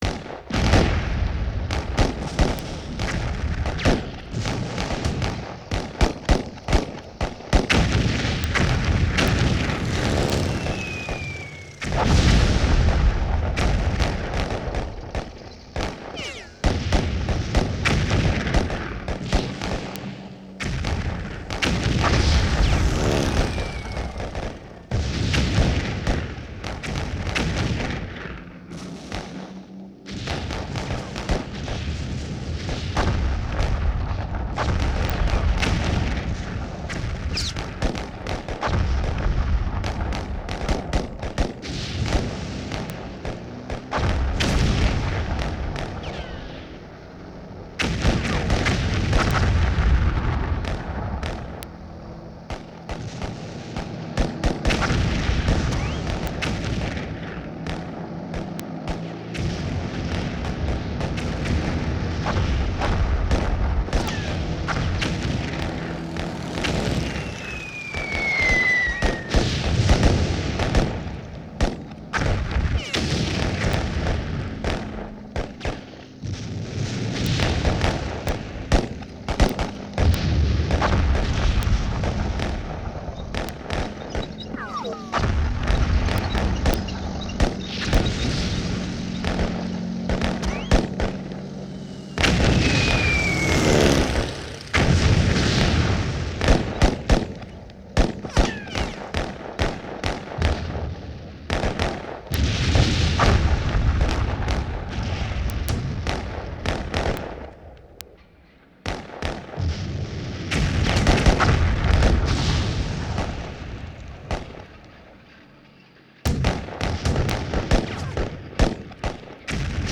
在战场环境的背景声 声音略小一点.wav